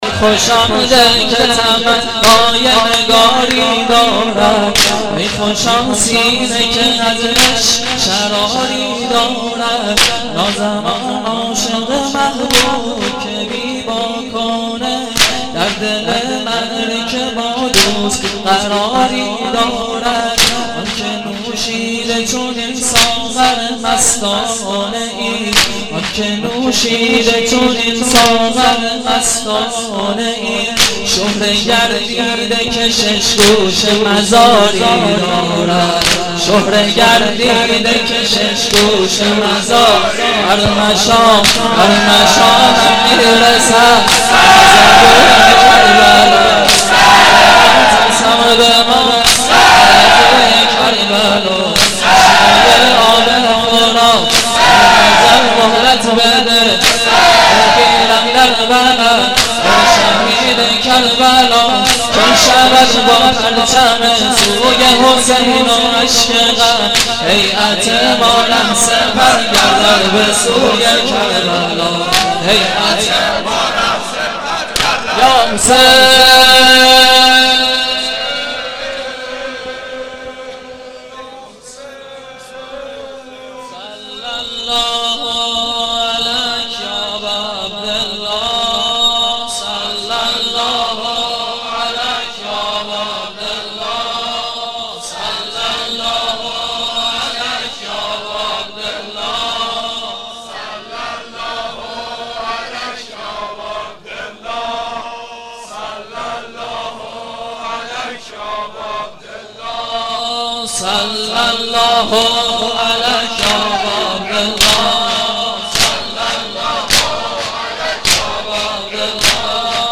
سنگین چکشی 2